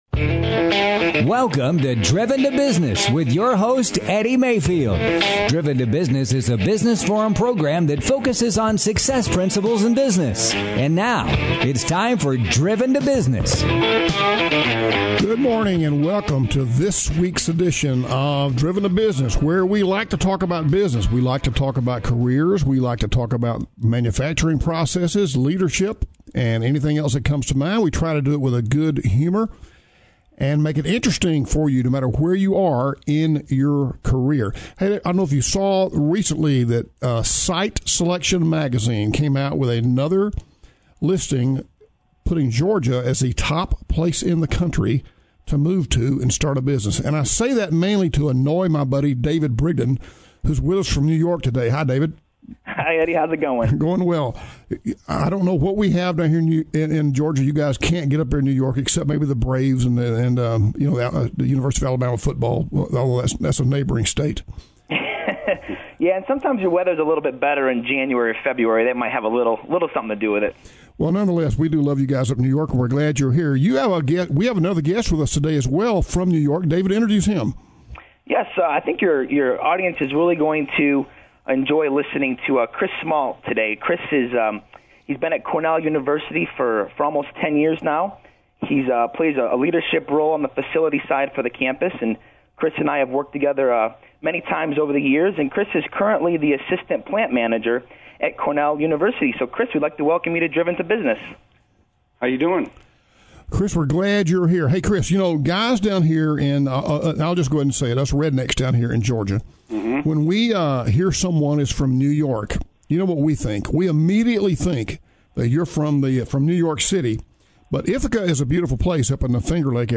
airs at 11 AM every Saturday on Atlanta’s business radio, WAFS, Biz 1190 AM.